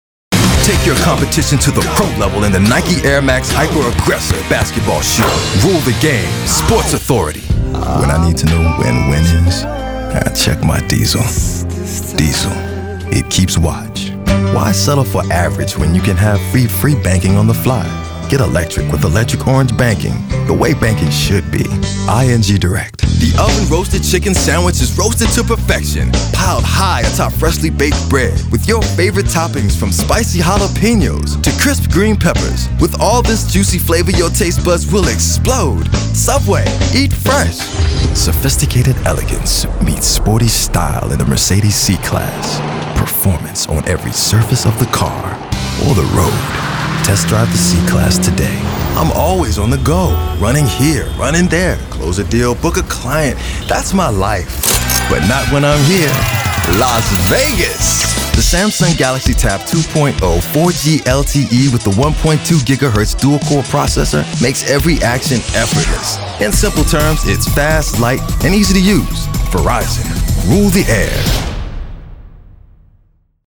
VO / Commercial